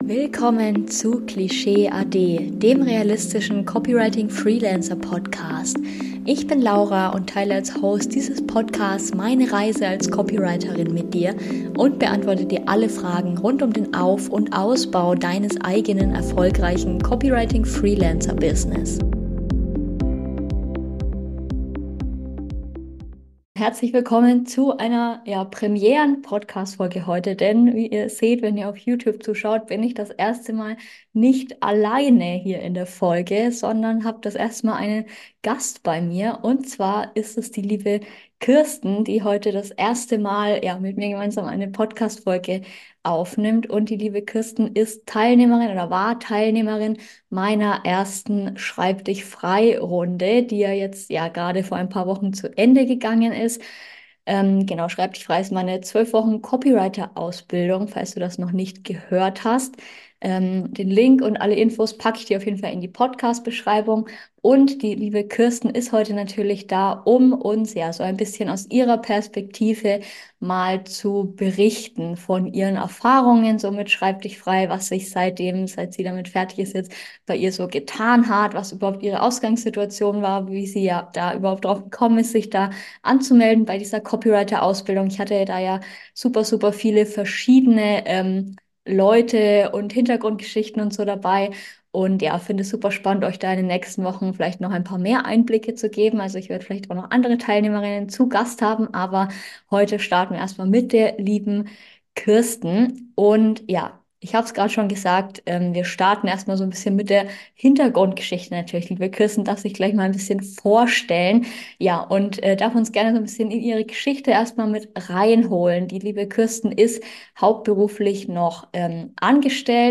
#44  Interview: Nebenberuflich als Copywriterin durchstarten?